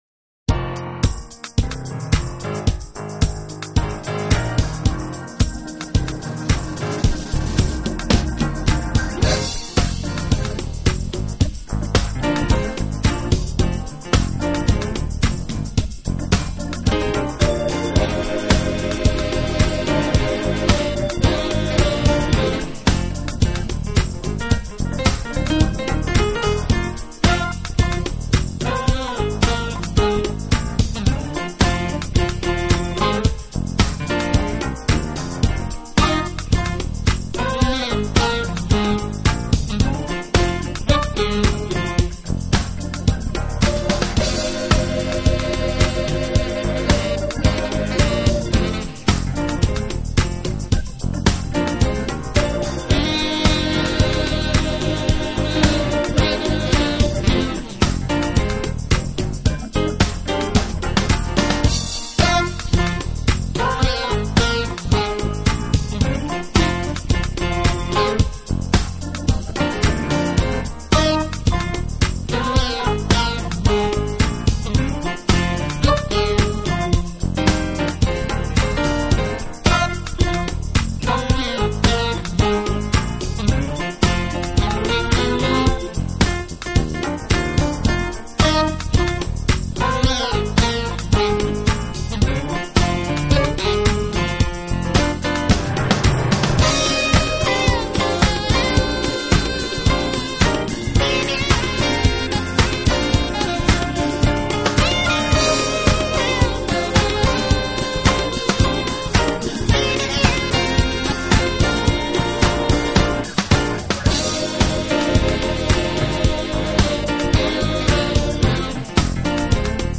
【经典Smooth Jazz合辑】
爵士乐所特有的诸如浪漫、轻柔、糜烂以及红酒般醉人的特性。